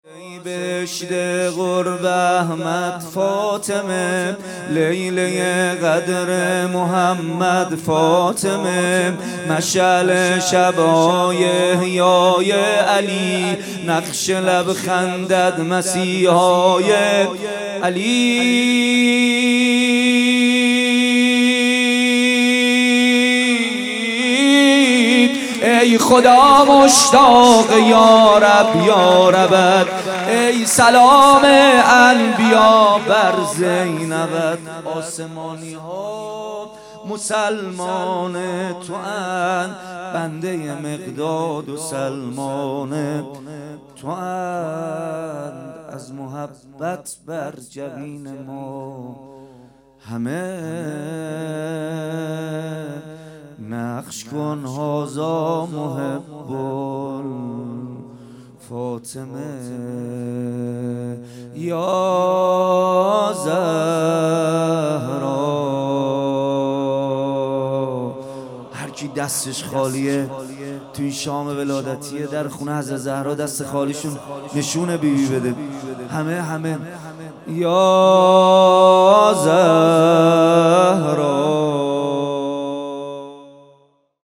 مداحی کربلایی محمدحسین پویانفر | ولادت حضرت زهرا (س) 97 | پلان 3